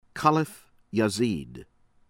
YAZDI, EBRAHIM EH-BRAH-HEEM    YAHZ-DEE